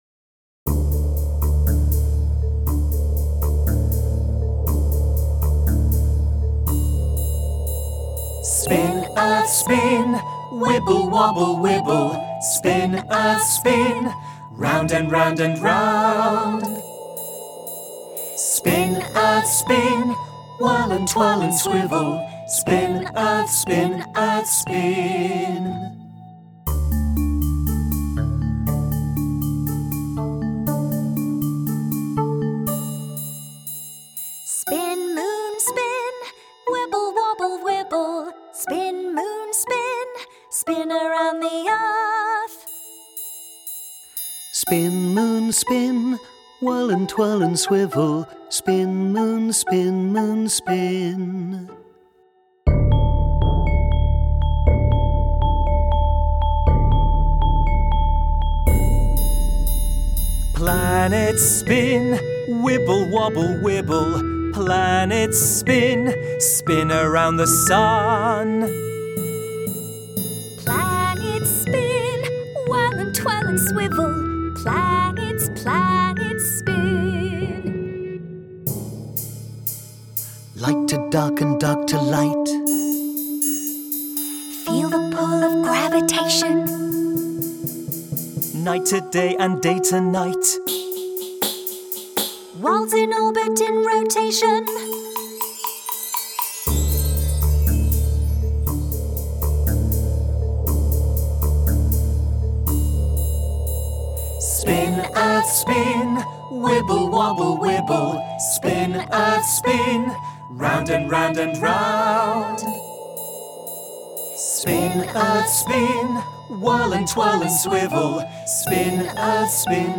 Guide vocal